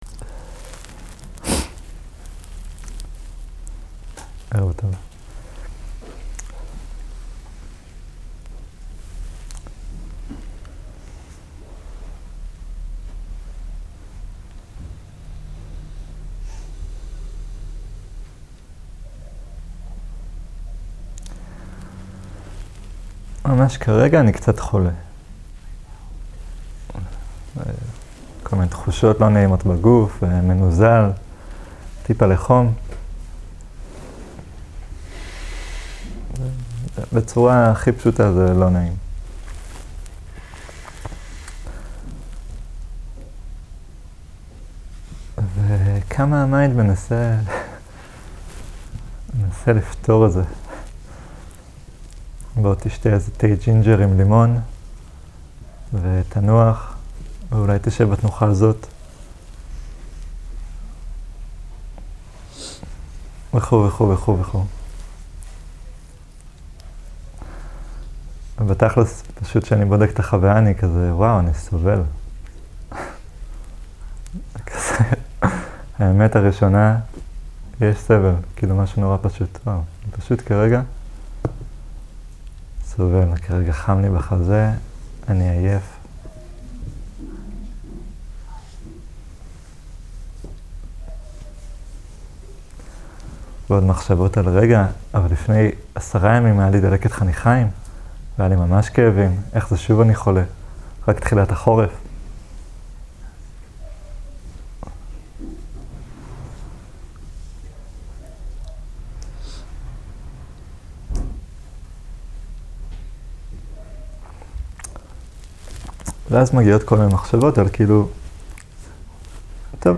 סוג ההקלטה: שיחות דהרמה
איכות ההקלטה: איכות גבוהה